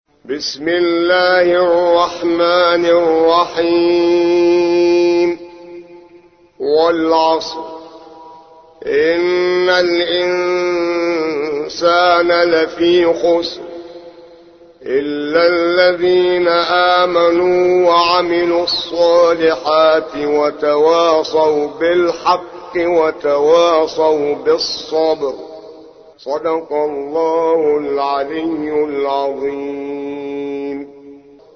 103. سورة العصر / القارئ